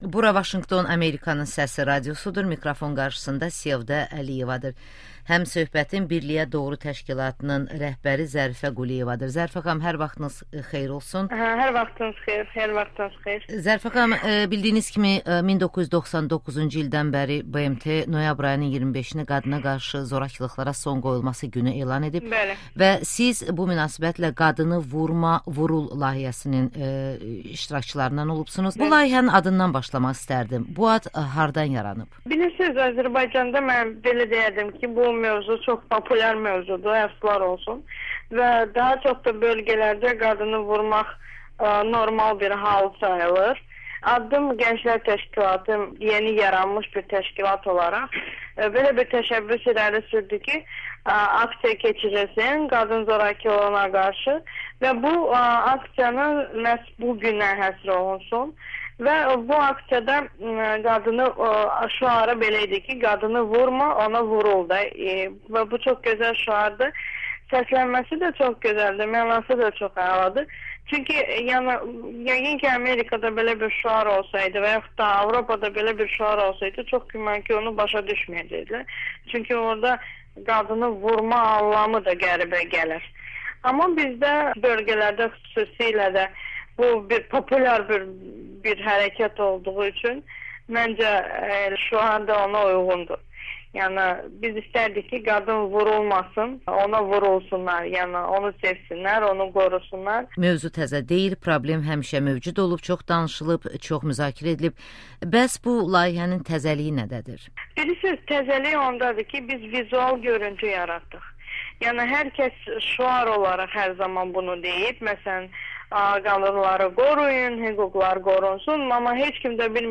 Noyabrın 25-i qadınlara qarşı zorakılıqlarla mübarizə günüdür [Audio-müsahibə & Fotoqalereya]